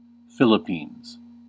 6. ^ /ˈfilɪpnz/
En-us-Philippines.ogg.mp3